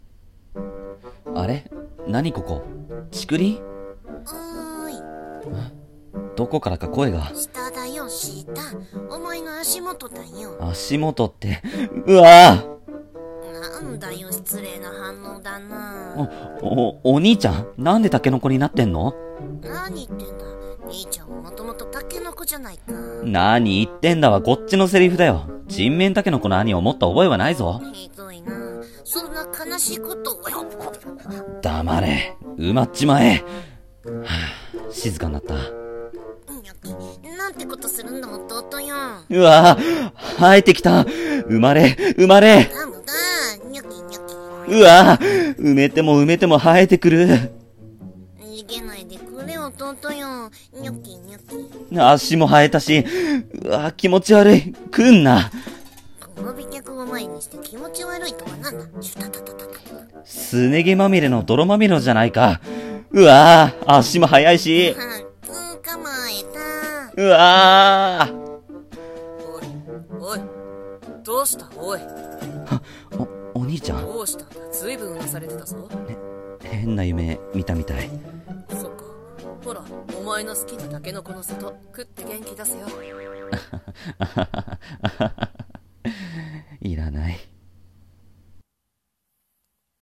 【ギャグ台本】